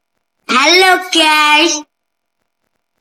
Efek suara Halo Guys
Kategori: Suara viral
Keterangan: Kamu bisa download efek suara Halo Guys ini dan gunakan di aplikasi edit video untuk menambahkan kesan humor pada video kamu.